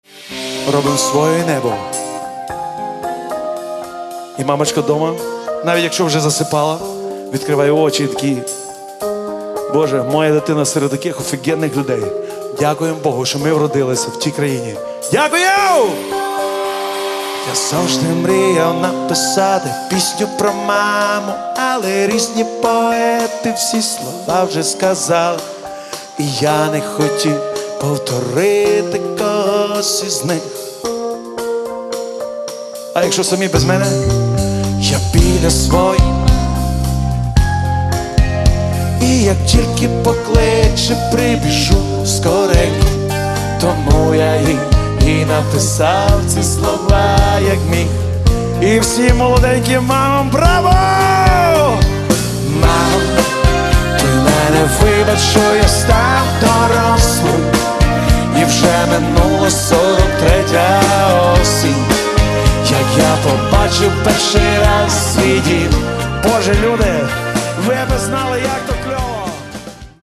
Каталог -> Рок и альтернатива -> Просто рок
записанный 4 апреля 2014 года концерт в Киеве